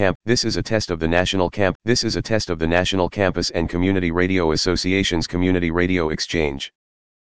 Status: Raw, unedited
Type: Interview
176kbps Mono